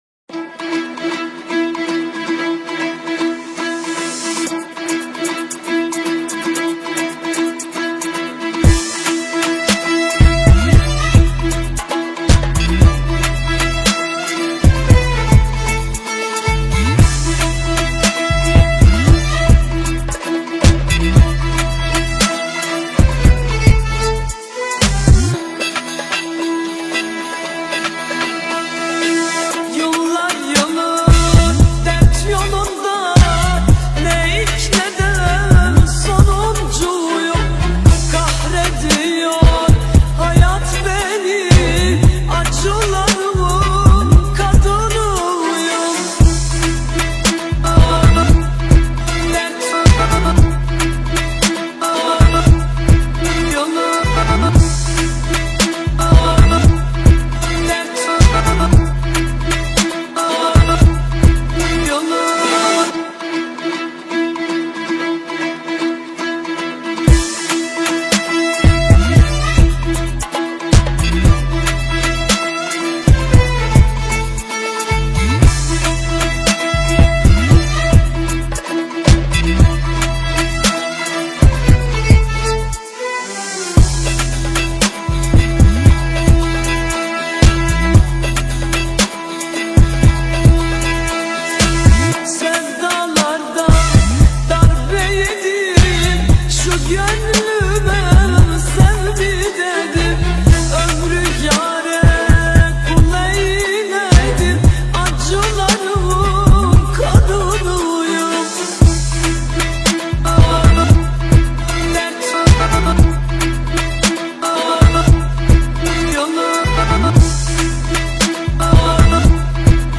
آهنگ ترکیه ای آهنگ غمگین ترکیه ای آهنگ نوستالژی ترکیه ای
ریمیکس